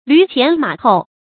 驢前馬后 注音： ㄌㄩˊ ㄑㄧㄢˊ ㄇㄚˇ ㄏㄡˋ 讀音讀法： 意思解釋： 比喻一切受人支配。